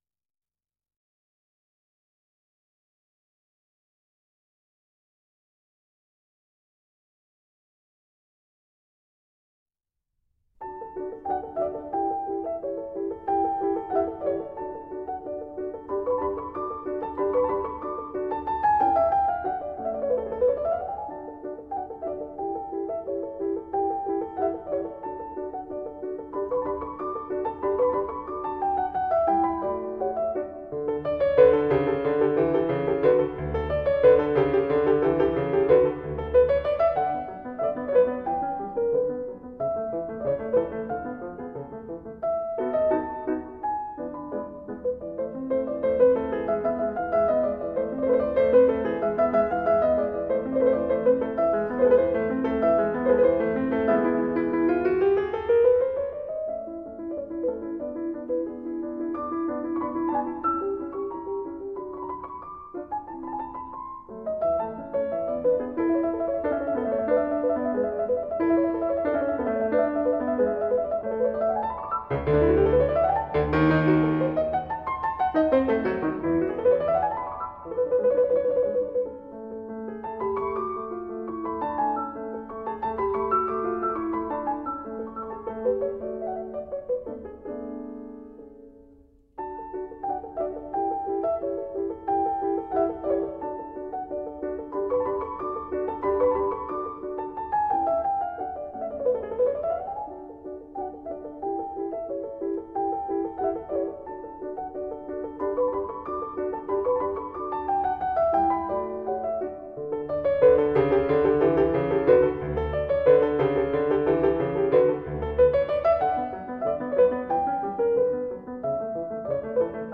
Rondo in D